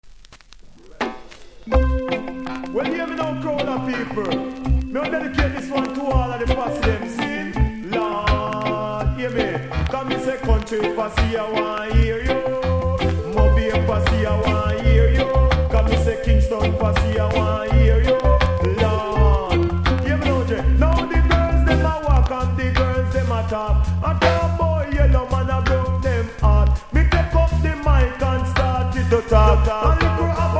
REGGAE 80'S
キズ多めでそれなりにノイズもありますので試聴で確認下さい。